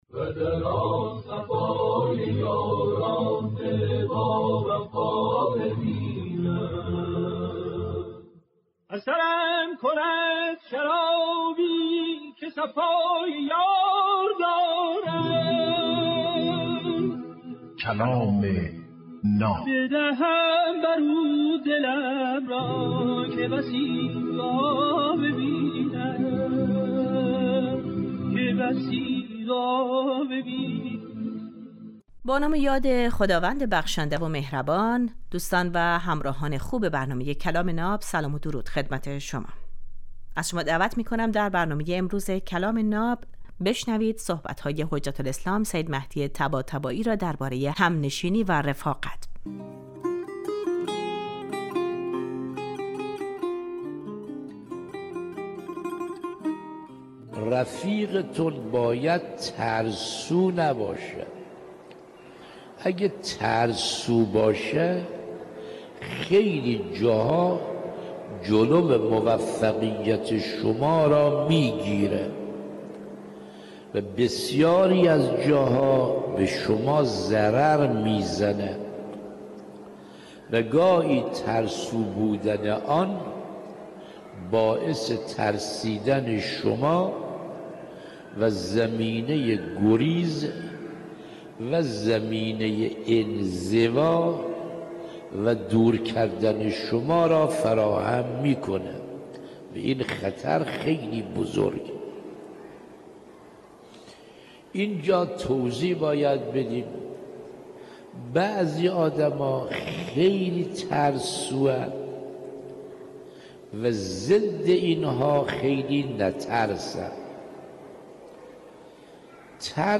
در این برنامه هر روز یک سخنرانی آموزنده کوتاه پخش می شود.